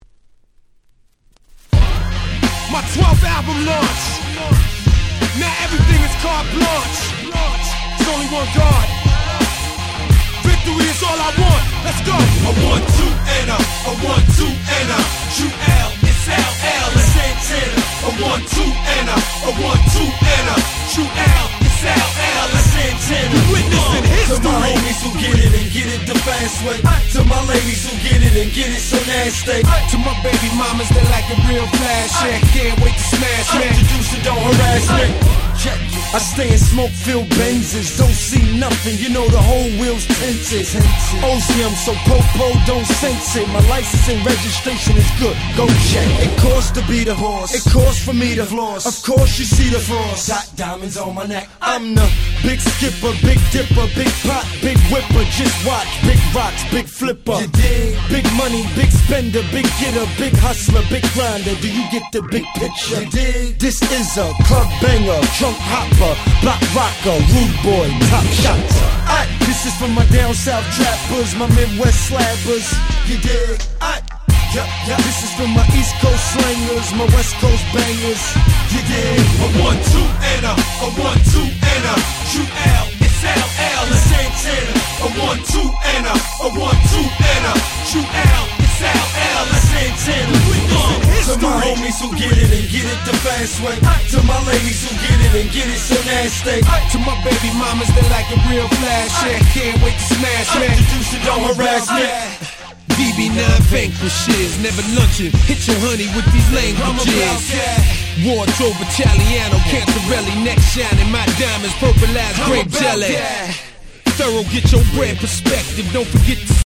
05' Smash Hit Hip Hop !!